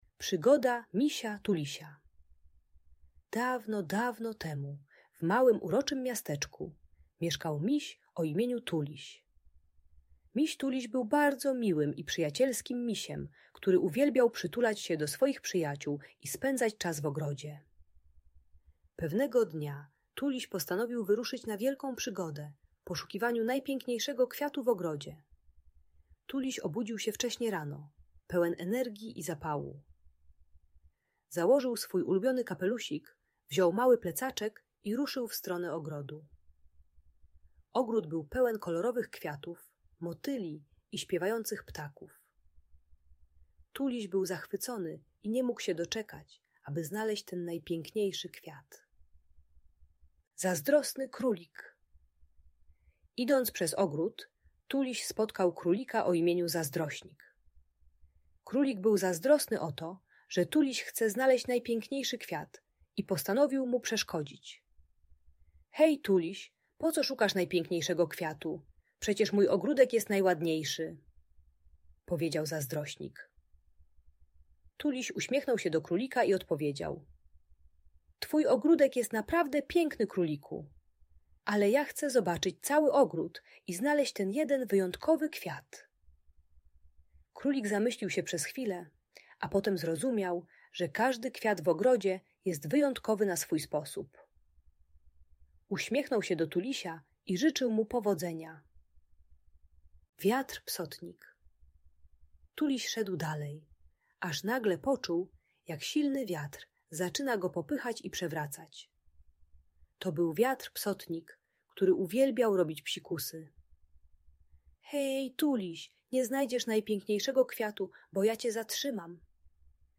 Przygoda Misia Tulisia - Bunt i wybuchy złości | Audiobajka